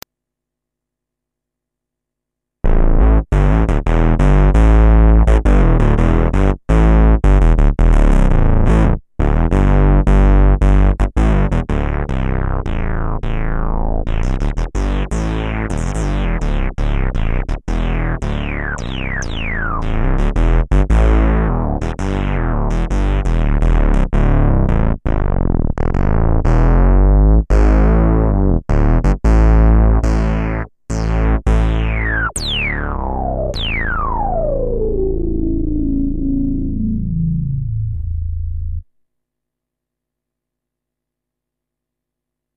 The lower switch is the beforementioned "release" switch, while there is another switch to turn on/off portamento which on a Moog is called "glide" Samples: Mini Moog doing the deep bass sound
BassMoog.mp3